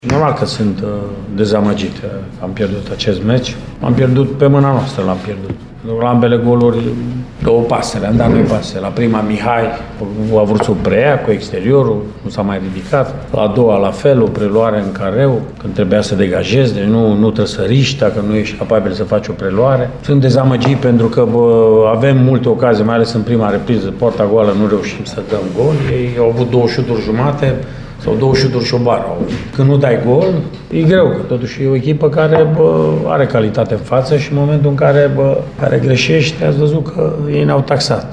Mircea Rednic s-a arătat dezamăgit la final, spunând că golurile lui Sepsi au fost marcate din pasele utiștilor: